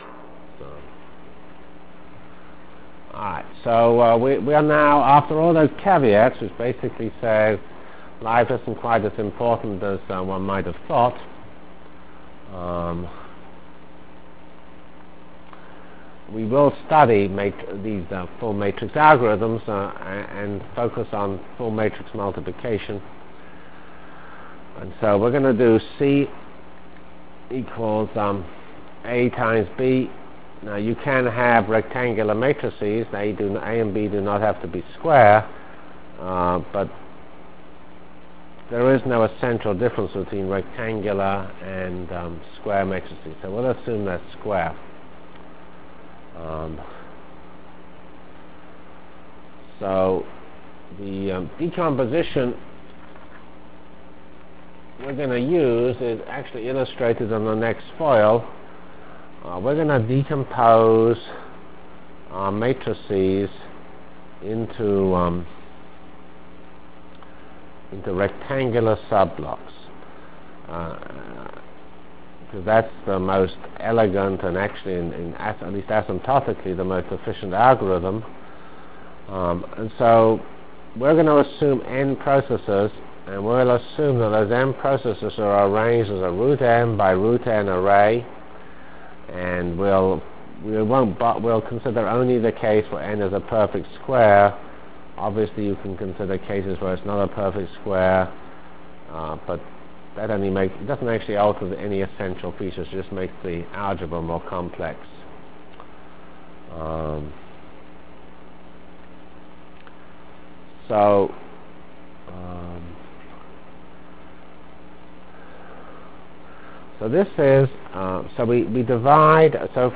From CPS615-Linear Programming and Whirlwind Full Matrix Discussion Delivered Lectures of CPS615 Basic Simulation Track for Computational Science -- 5 Decemr 96.